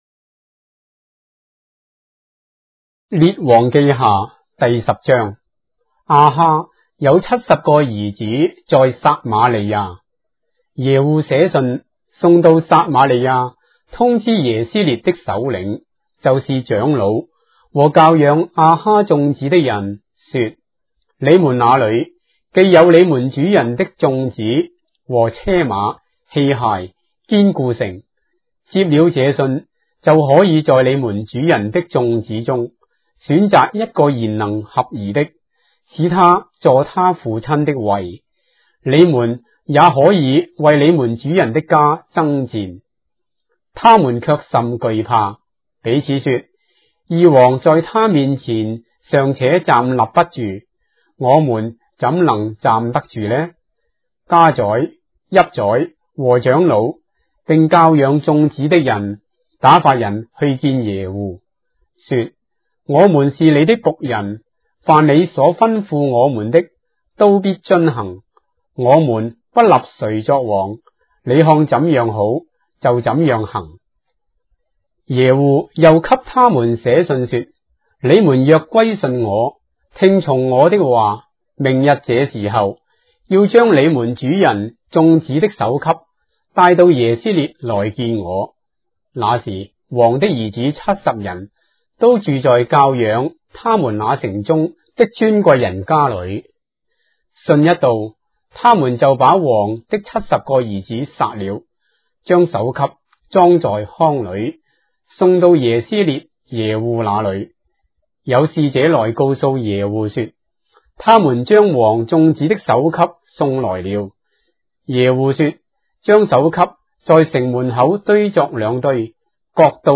章的聖經在中國的語言，音頻旁白- 2 Kings, chapter 10 of the Holy Bible in Traditional Chinese